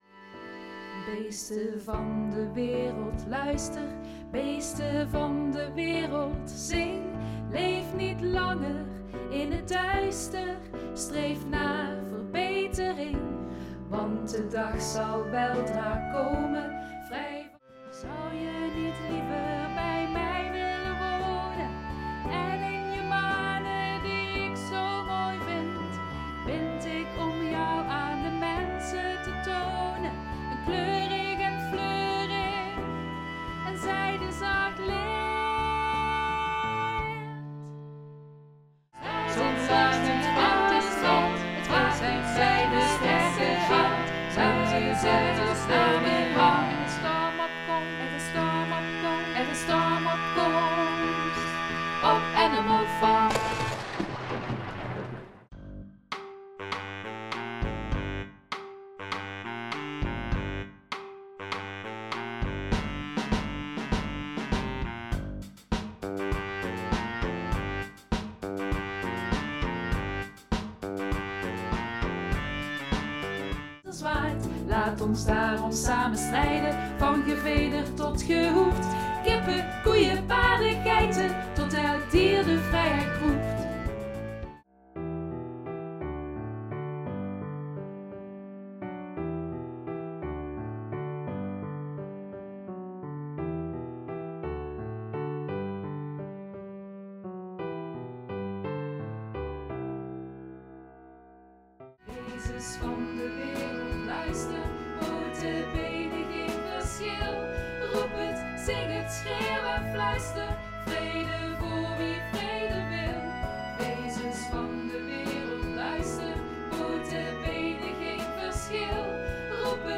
Van 6 songteksten naar beestachtige sfeer
Beesten van de wereld: hoopvol openingsnummer, gezongen door het oude varken
Lint in mijn haar: melancholisch lied over een stil verlangen, gezongen door het luxepaardje
Storm op komst: stormachtig koornummer dat eindigt in een grote catastrofe
Beesten van de wereld: reprise in snelle stijl
Klaver komt tot inzicht: wijsheidsnummer in klassieke pianostijl